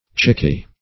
Search Result for " chicky" : The Collaborative International Dictionary of English v.0.48: Chicky \Chick"y\ (ch[i^]k"[y^]), n. A chicken; -- used as a diminutive or pet name, especially in calling fowls.